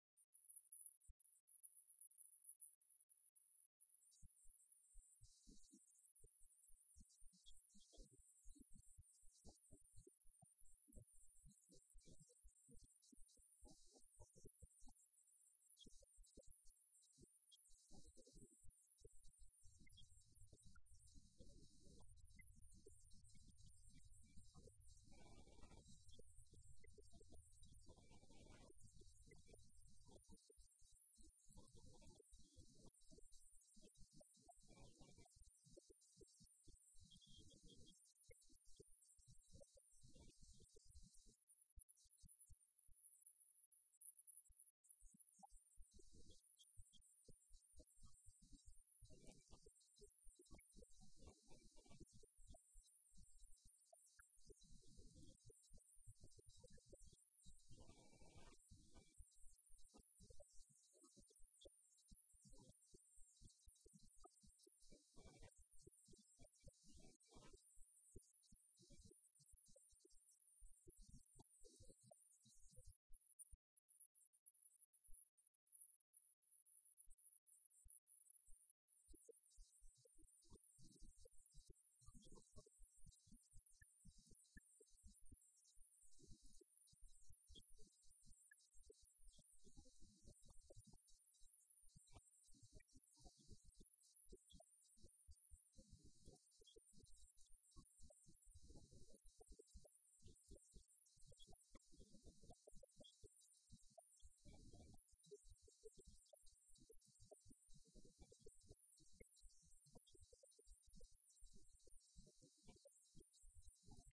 Ismael Bordagaray, intendente de Famatina, por Radio Universidad de Santa Fé